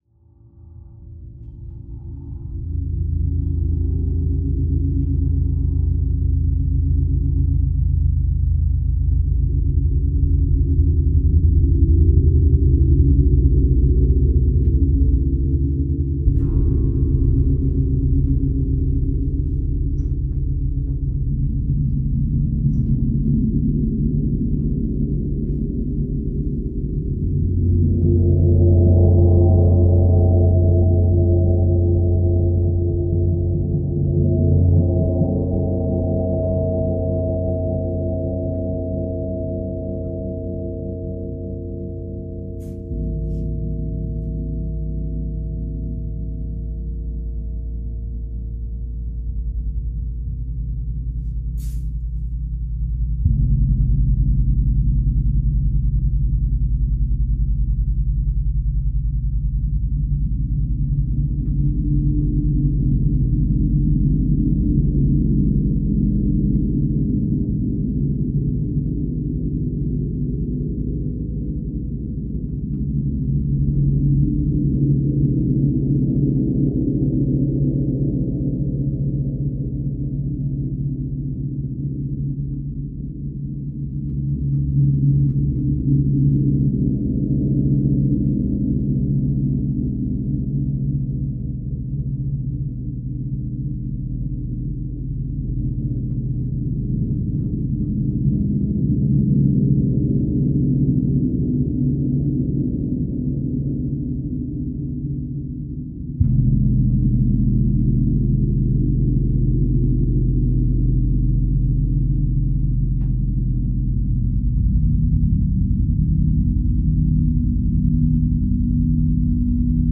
Explore the beauty of cosmic sound.
Cosmic… 10/19/2025 Explore the Tranquility of Cosmic Gong Music Welcome to the World of Cosmic Sound Discover a serene and immersive experience as you listen to the beautifully harmonious… 10/19/2025 Sound Healing Experience cosmic gong music for relaxation and healing.
sunday-practice-edited-10_19.mp3